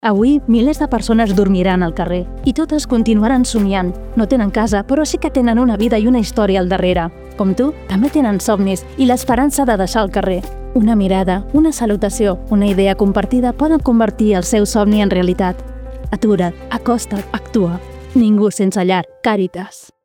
Cunya radio